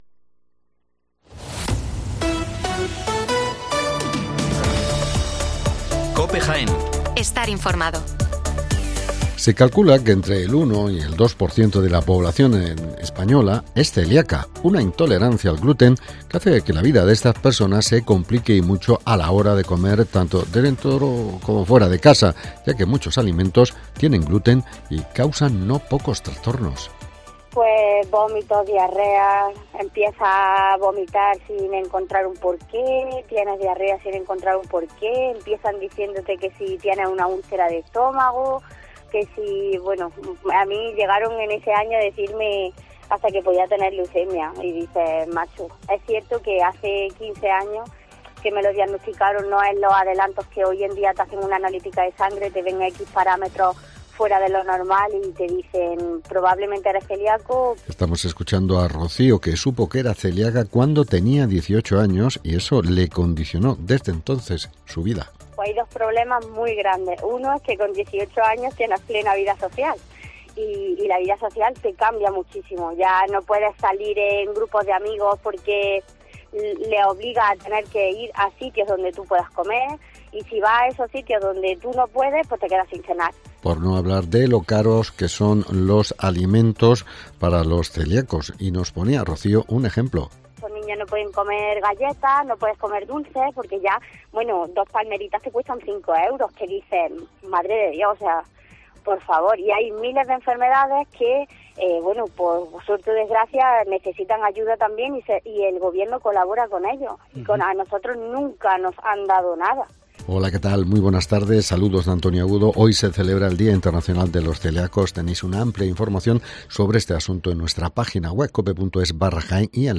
Las noticias locales del 16 de mayo de 2023